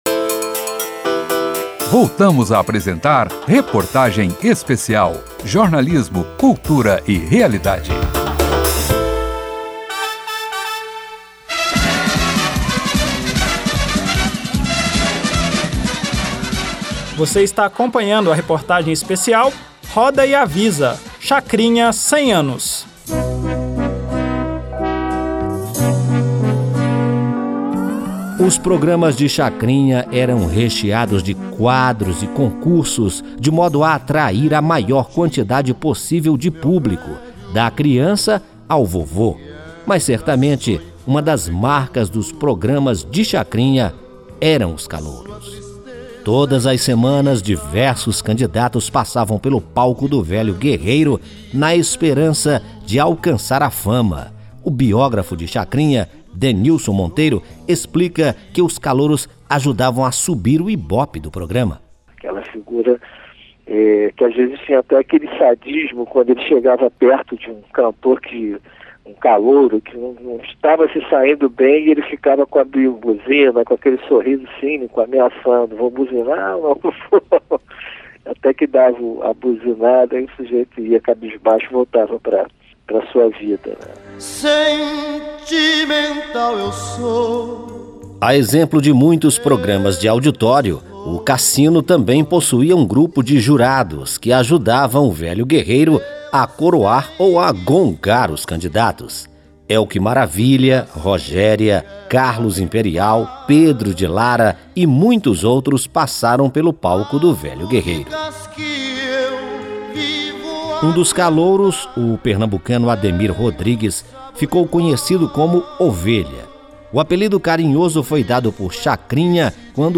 A reportagem especial contará com trechos de uma entrevista concedida por Chacrinha em 1980 ao Museu da Imagem e do Som de São Paulo.